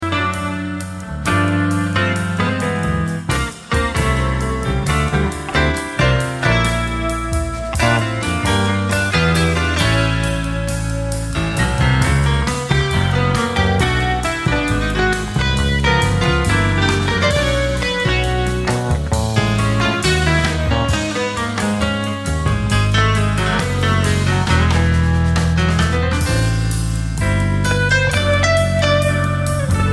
Piano
Soprano & Tenor sax
Double bass
Drums